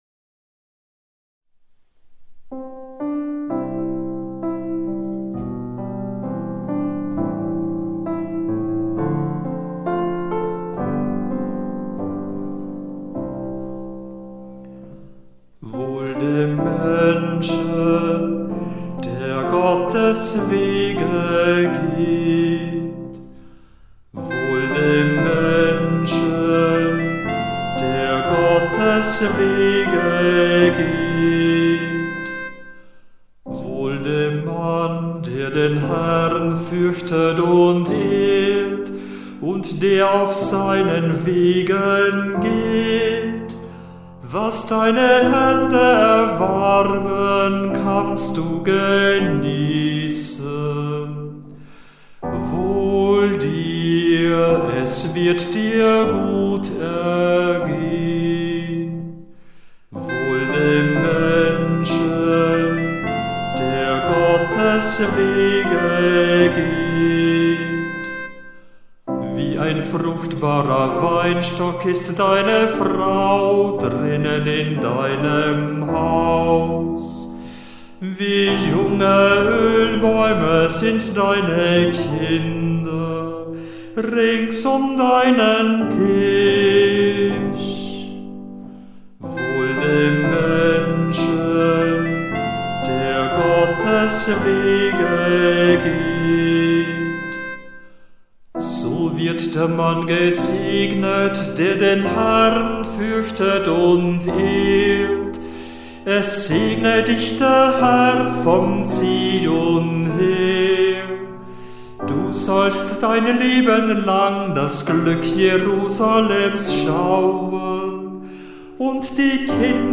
Hörbeispiele aus verschiedenen Kantorenbüchern